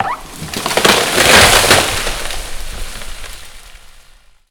10a-tree-falling-down.wav